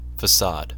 Ääntäminen
Vaihtoehtoiset kirjoitusmuodot façade Ääntäminen UK : IPA : /fə.ˈsɑːd/ US : IPA : /fə.ˈsɑːd/ Aus: IPA : /fəˈsaːd/ Haettu sana löytyi näillä lähdekielillä: englanti Käännös Ääninäyte Substantiivit 1.